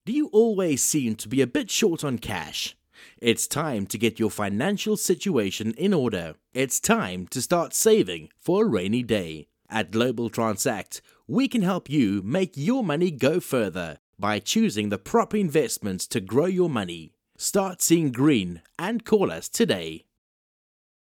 My voice is clear, engaging, versatile, and perfect for:
Fast turnaround. Studio-quality sound. No fuss, just clean, compelling audio tailored to your vision.